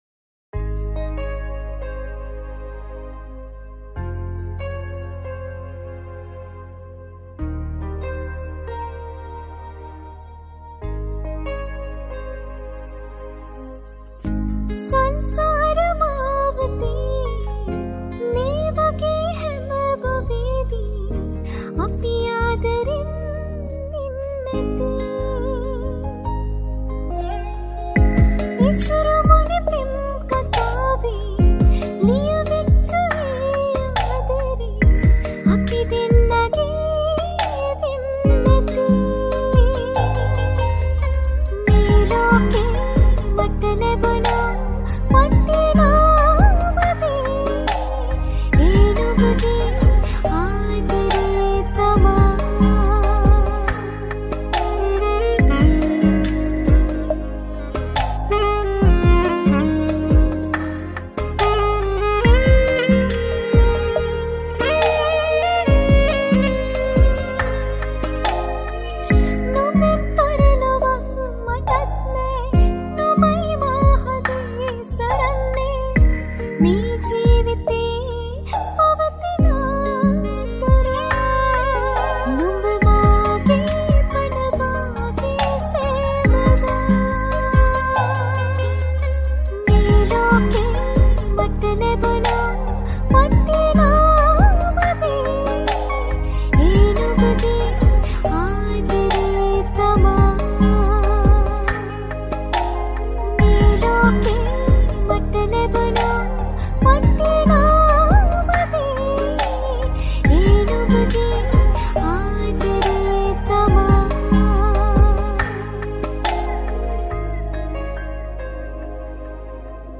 High quality Sri Lankan remix MP3 (2.9).